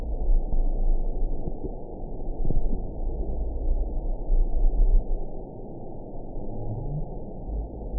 event 922921 date 05/06/25 time 23:49:33 GMT (1 month, 1 week ago) score 9.08 location TSS-AB10 detected by nrw target species NRW annotations +NRW Spectrogram: Frequency (kHz) vs. Time (s) audio not available .wav